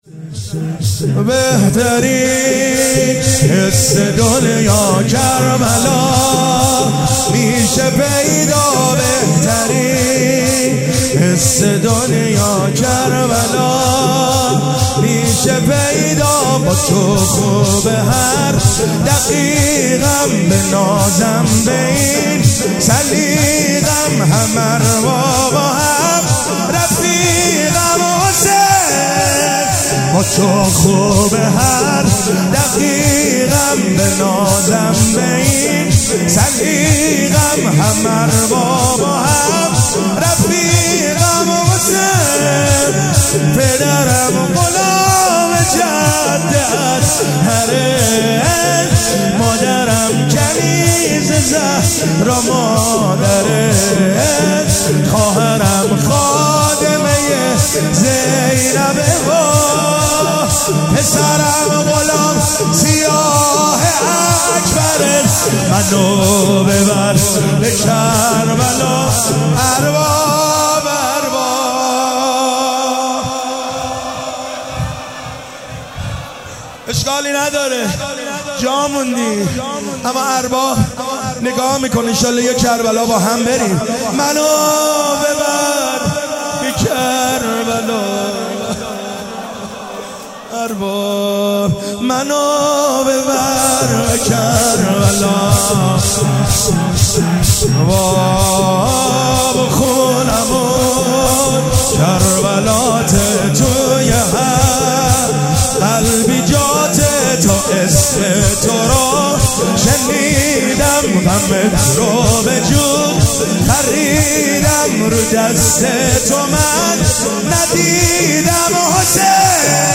شور-بهترین حس دنیا.mp3